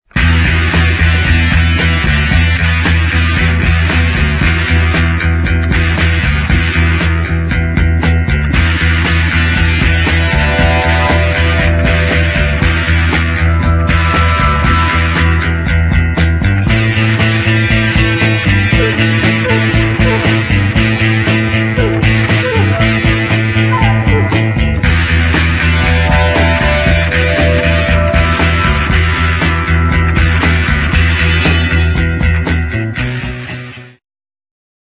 exciting medium instr.